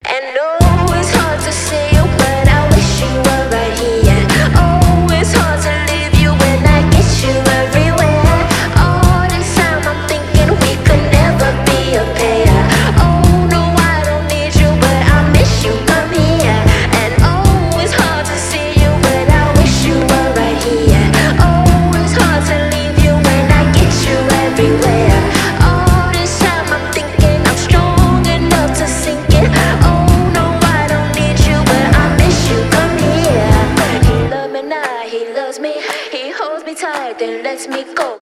rnb , поп